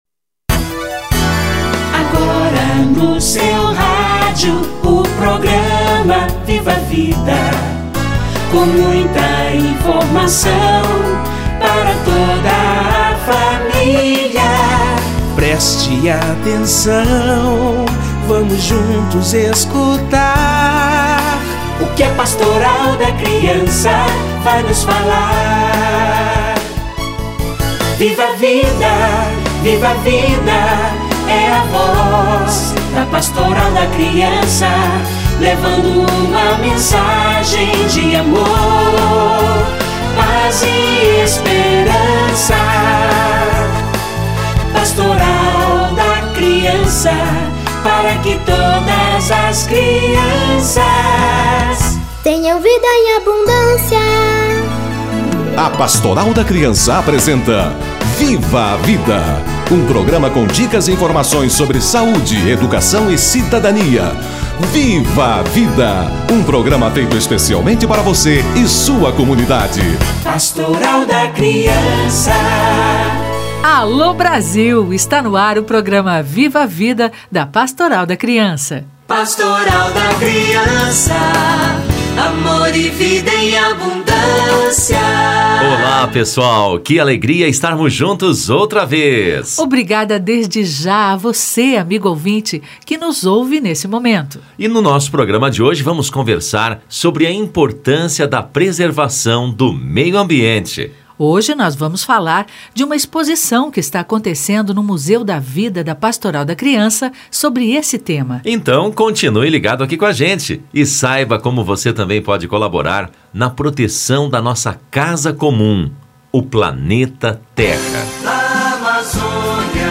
Biomas brasileiros e defesa da vida - Entrevista